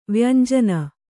♪ vyanjana